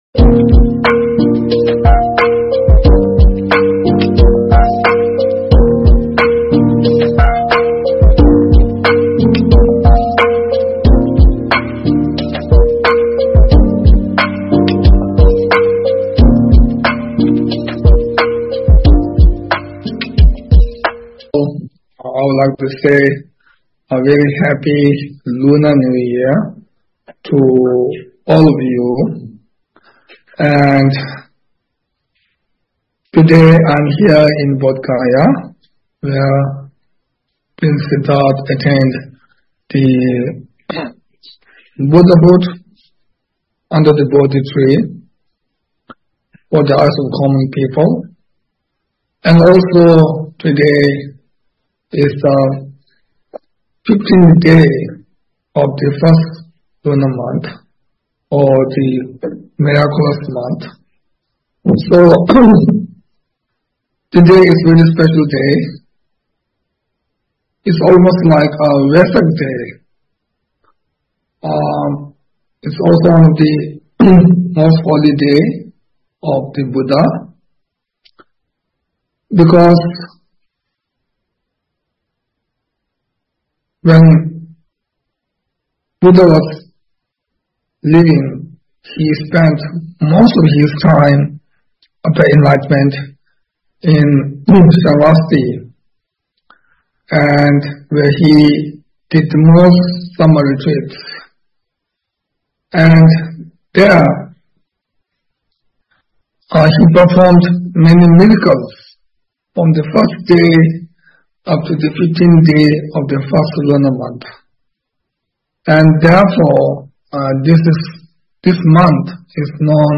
Studying the Aparimitāyurjñāna Sūtra on Chotrul Düche_H.H. the 42nd Sakya Trizin's Dharma Teaching Given in 2024_The Sakya Tradition
Venue: Bodhgaya, India (live-streamed)